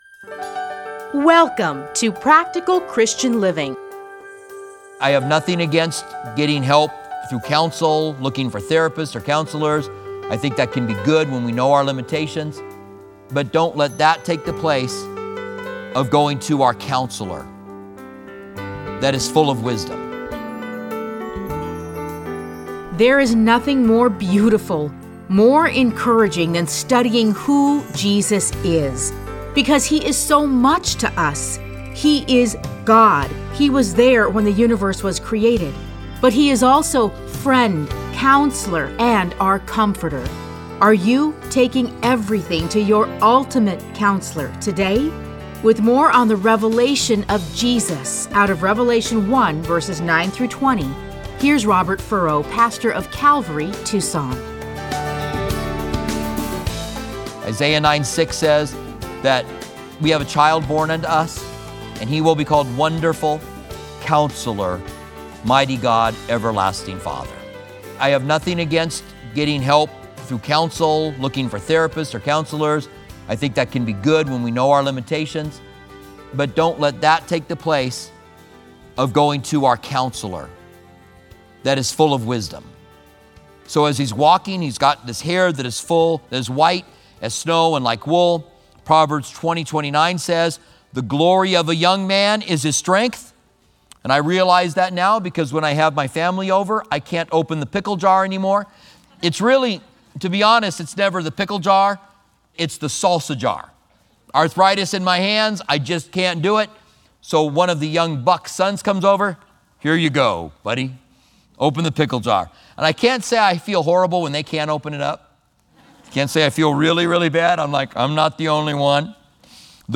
Listen to a teaching from Revelation 1:9-20.